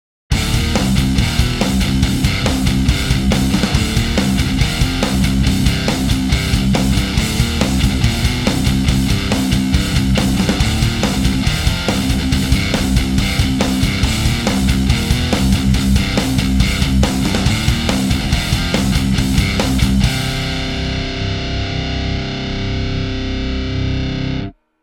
METAL Dime 2.mp3